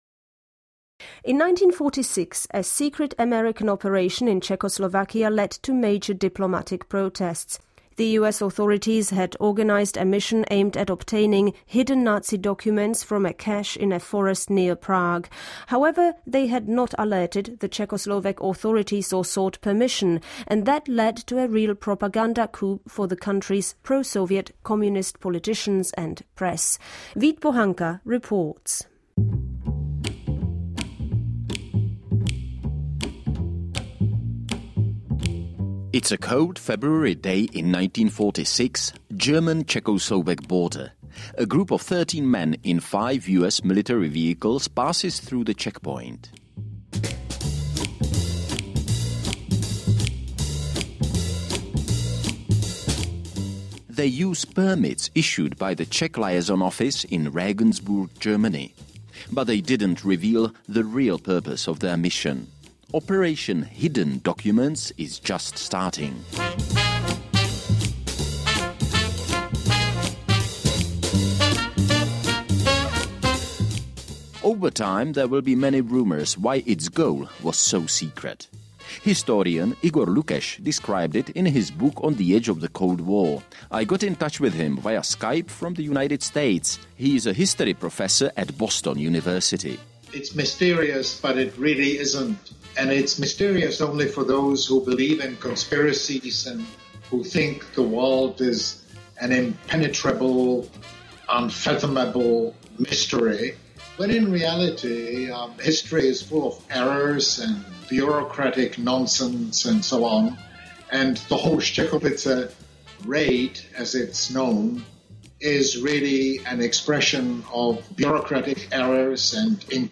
was interviewed for a recent radio segment on a United States Army Intelligence operation in post-World War II Czechoslovakia.